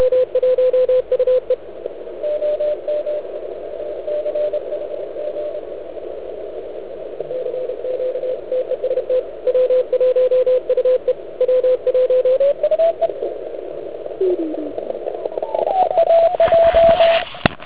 Band je klasicky "panelákově zaprskán asi na S9.